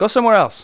sci-bumping6.wav